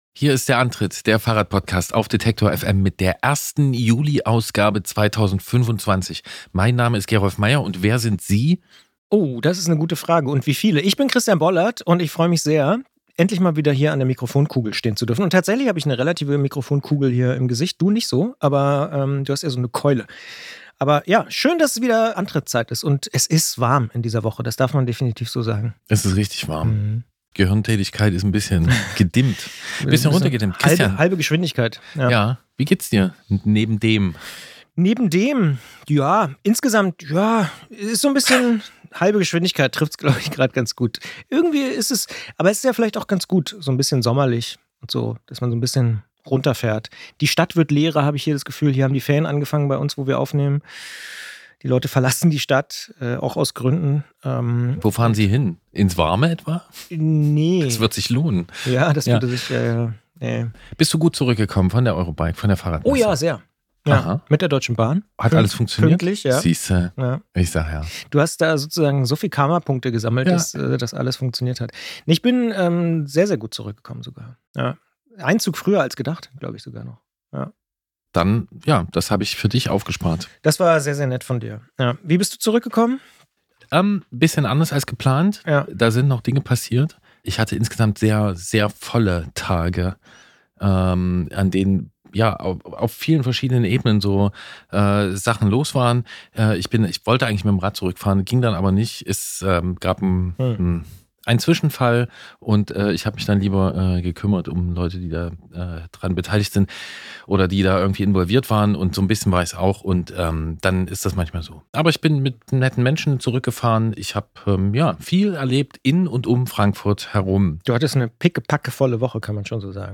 Mitte Juni hat in Leipzig das erste „Antritt“-Treffen stattgefunden. Wir haben mit unseren Unterstützerinnen und Unterstützern gesprochen.